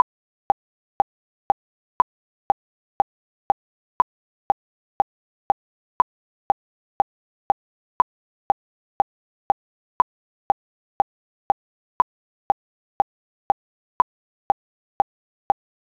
click2.wav